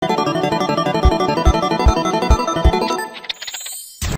spin-sound.m4a